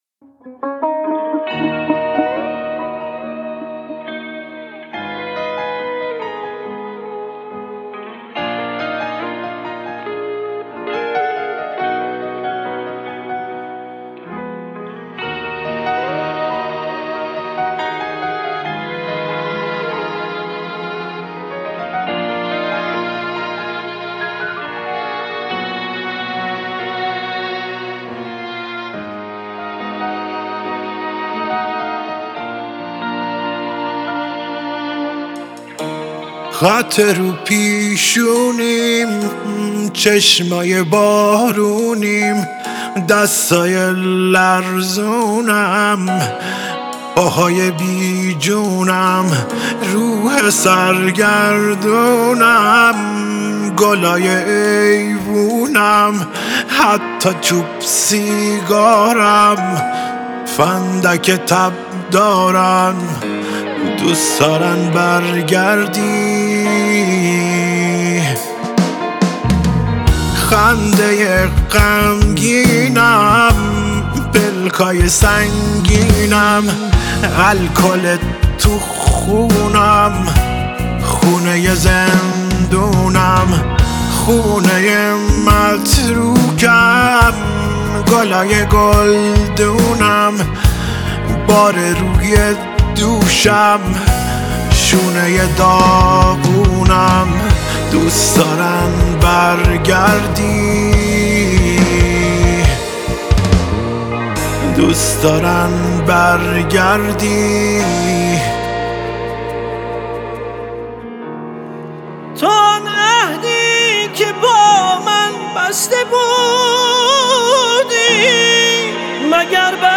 گیتار الکتریک
پیانو
کیبورد